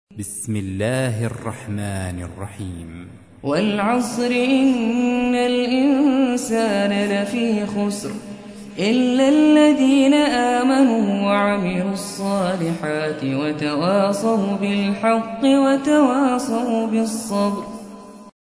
موقع يا حسين : القرآن الكريم 103. سورة العصر - سورة مكية ، عدد آياتها : 3 لحفظ الملف في مجلد خاص اضغط بالزر الأيمن هنا ثم اختر (حفظ الهدف باسم - Save Target As) واختر المكان المناسب